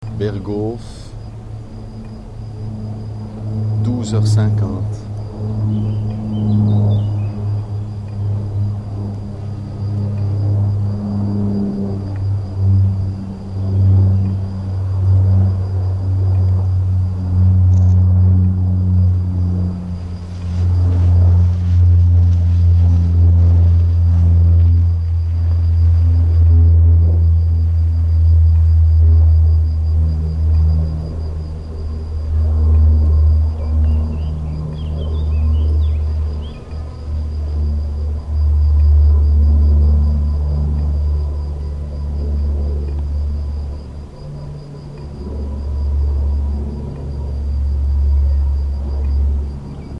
Il y a donc une certaine distribution des bruits, qui peut être très relative quand la densité du trafic augmente.
Il est 13h 17, le 2 juin, l’avion passe sur le chemin dans les conditions de survol de Delémont, Courroux, Vicques, Vermes, Courchapoix, Corban.